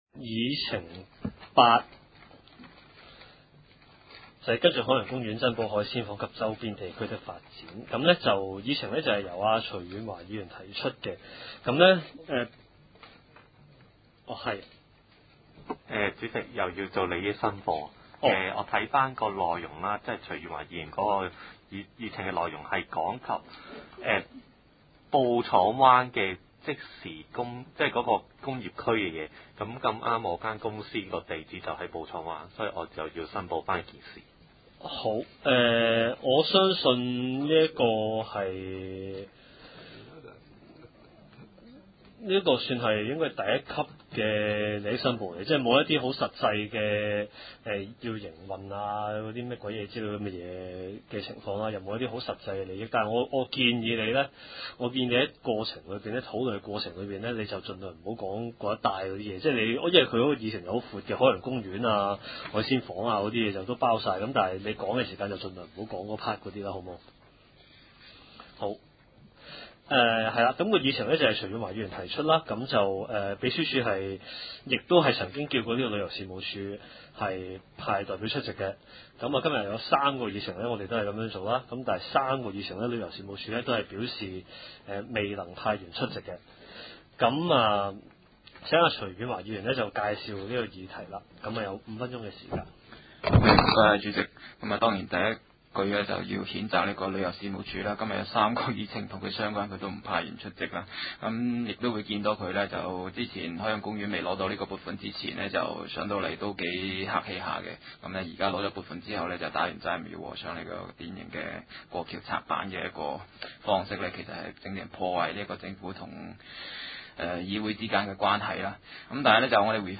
區議會大會的錄音記錄